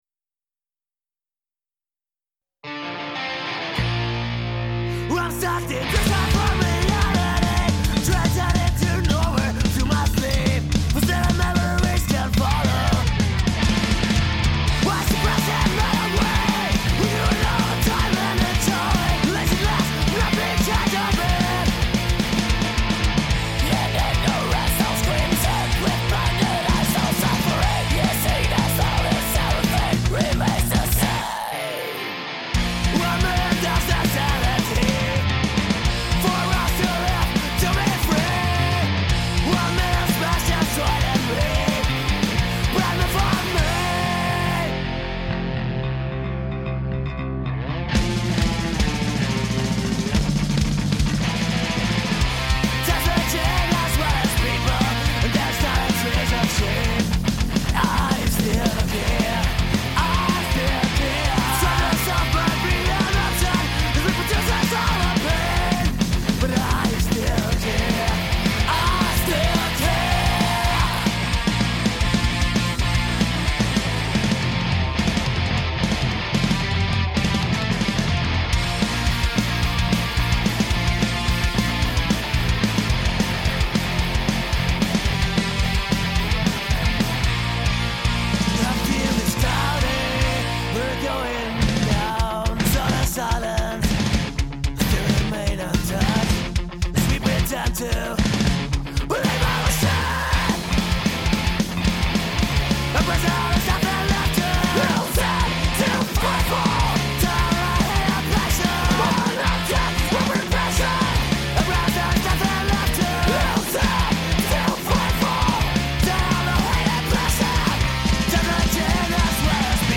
Letzte Episode Interview mit ants! beim Punk Rock Holiday 1.6 11. August 2016 Nächste Episode download Beschreibung Teilen Abonnieren ants! aus Graz haben uns in unserer Morning Show beim Punk Rock Holiday besucht.
interview-mit-ants-beim-punk-rock-holiday-1-6-mmp.mp3